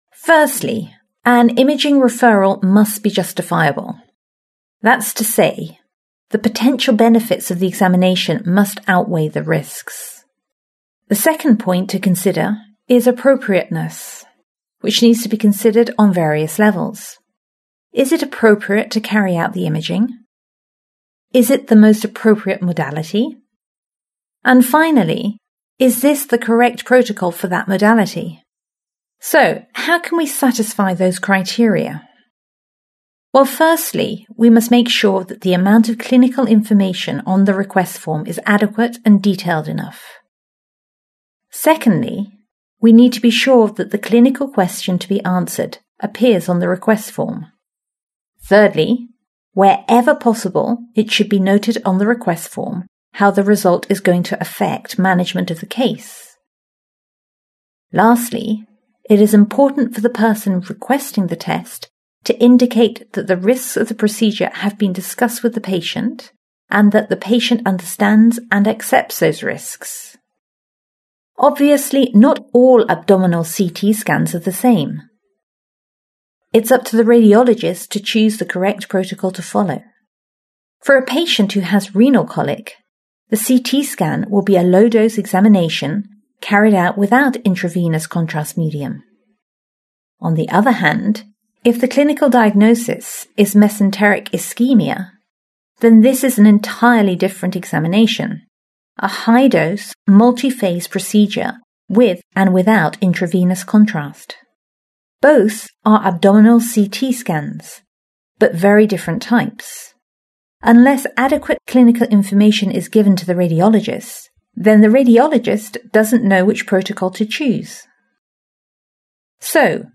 10. Listen: Imaging referrals.
Listen to part of a talk from a training video for medical practitioners. The talk is about making imaging requests, or referrals.